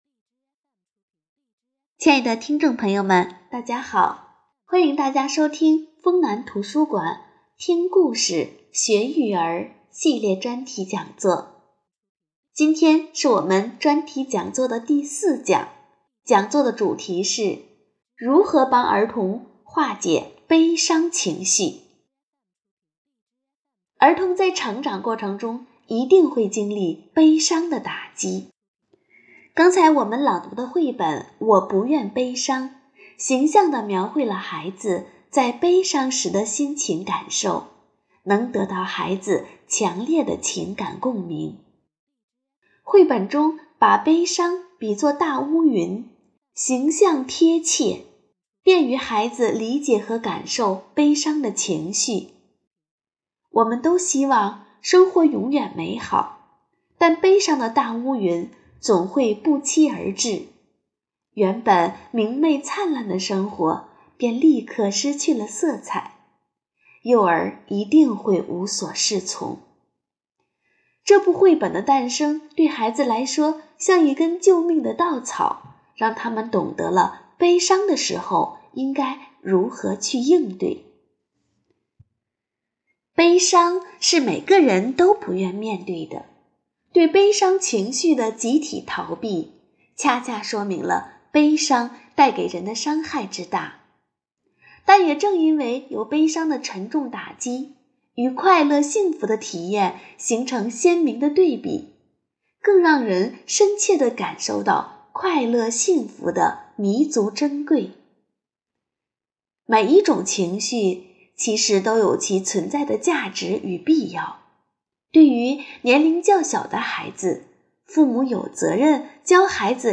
【讲座】《听故事·学育儿》专题讲座四：如何帮儿童化解悲伤情绪